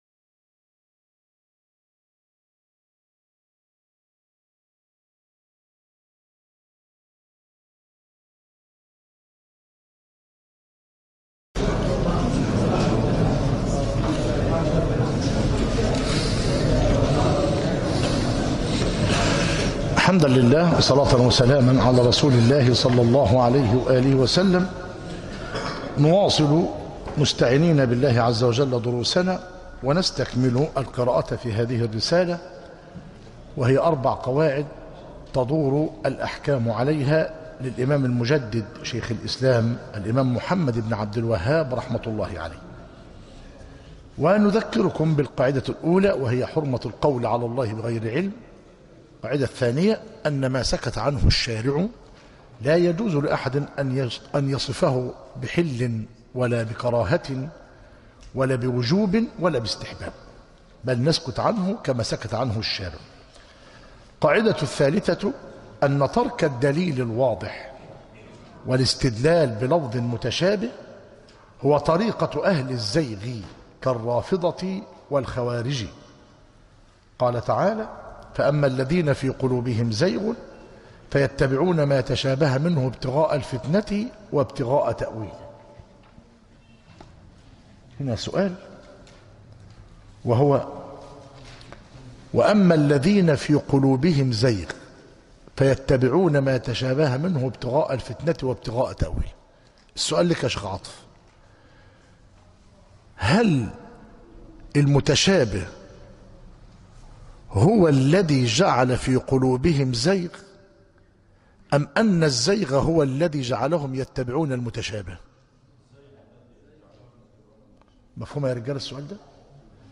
مسجد التقوى
المحاضرة الثامنة عشر